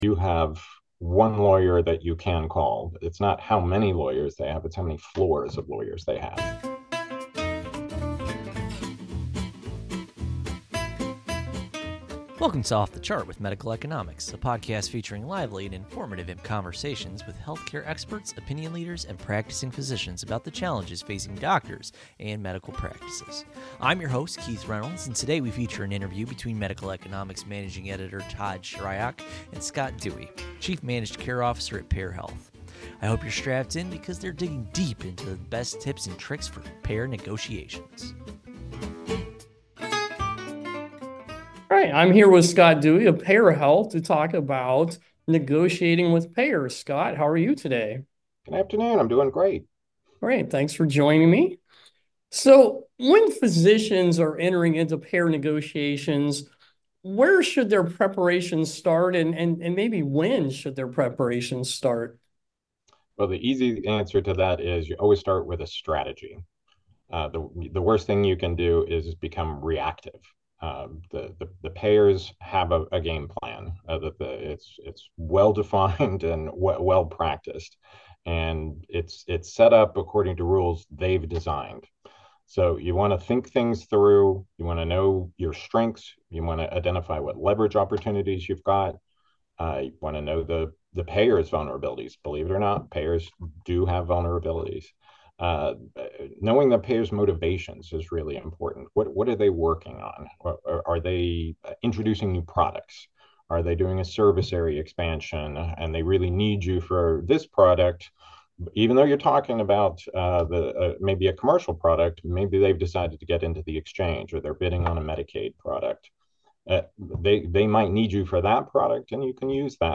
Gypsy Jazz Swing
Overview of the podcast and introduction of the speakers discussing payer negotiations. Starting Preparation for Negotiations (00:01:03) Importance of having a strategy and understanding leverage before entering negotiations.